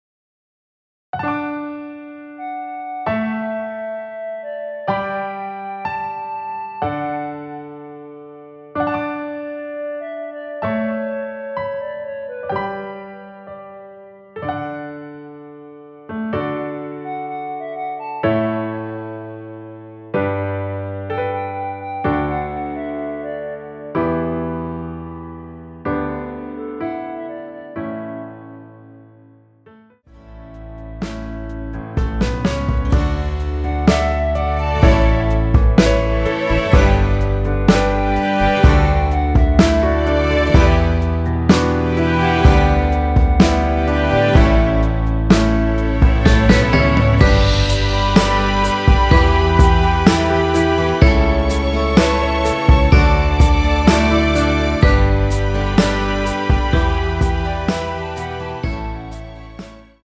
원키에서(+2)올린 멜로디 포함된 MR입니다.
앞부분30초, 뒷부분30초씩 편집해서 올려 드리고 있습니다.
중간에 음이 끈어지고 다시 나오는 이유는